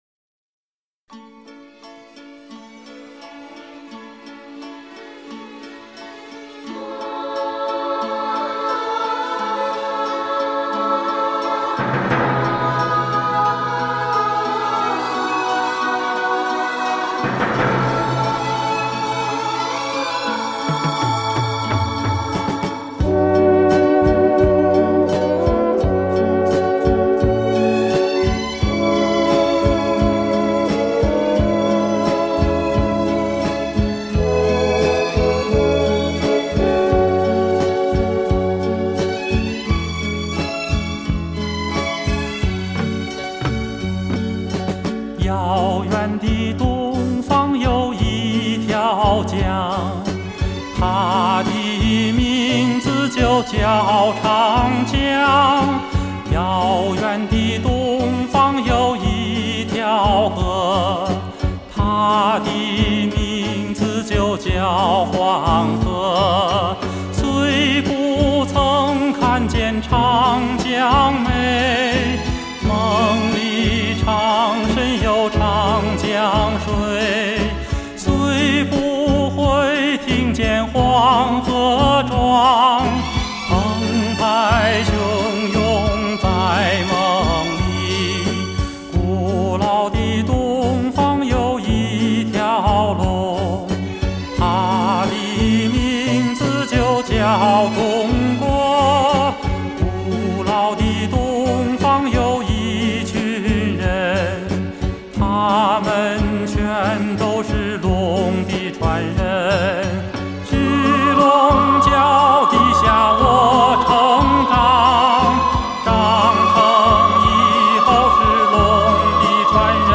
那歌声...那旋律...悠扬飘荡...